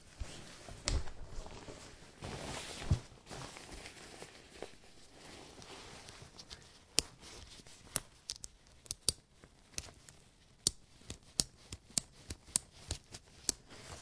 描述：在这里，我试图收集我在家里发现的所有扣件。其中大部分在夹克衫上，一个手提包里有啷个球，还有一些雪裤。
Tag: 点击 服装和-配件 扣紧固件